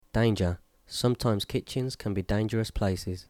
2 Danger  ˈdeɪndʒə